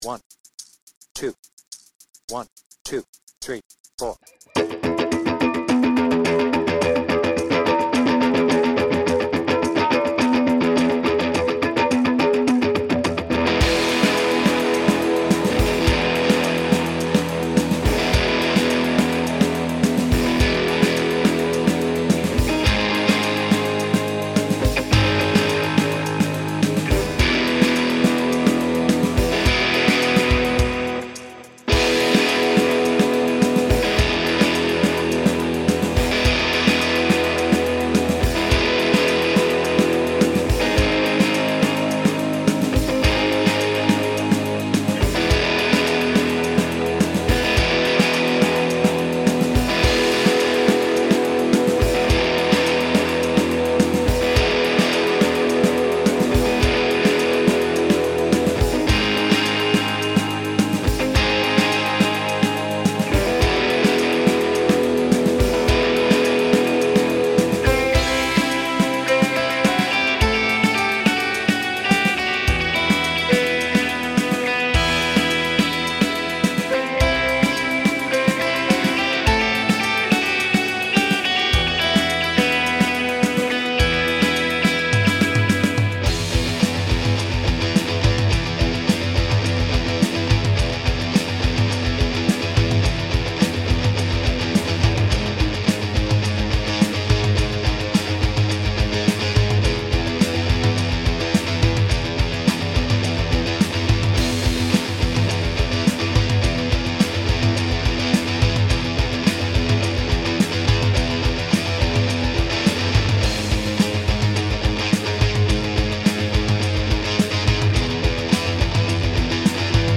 BPM : 106
Tuning : E